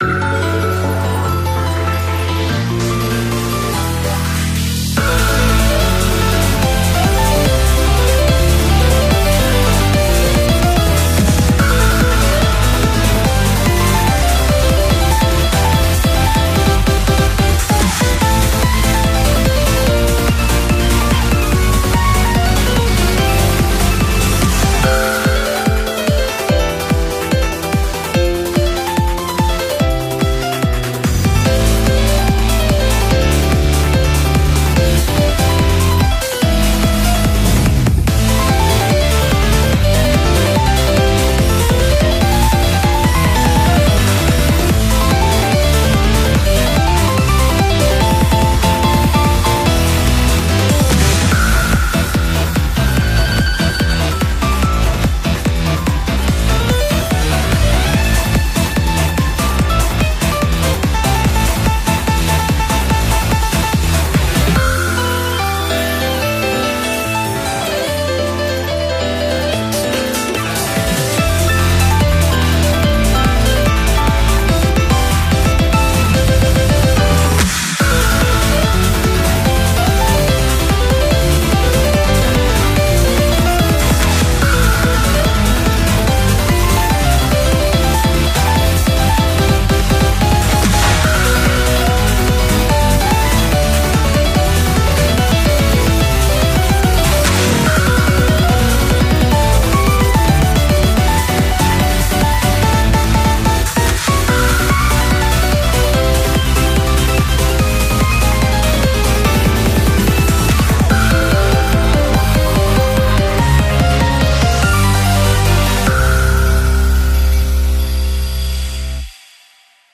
BPM145
MP3 QualityLine Out